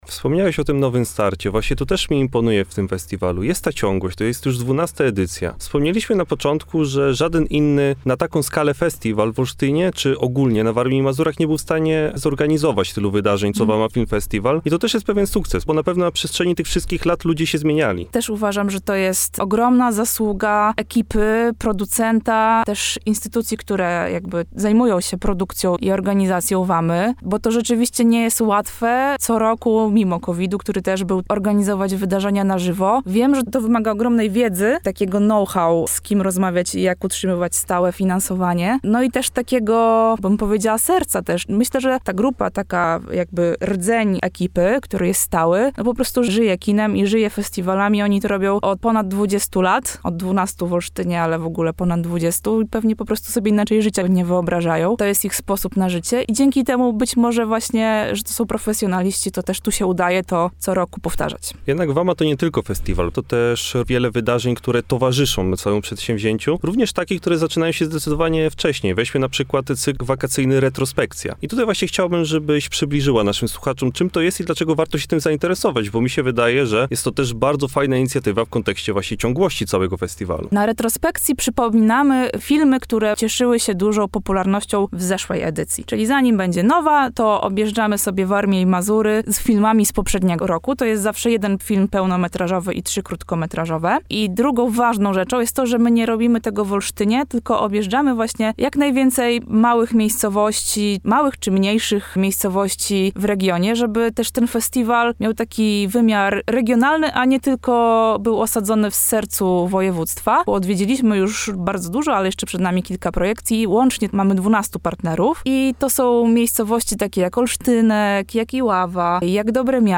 – mówiła w naszym studiu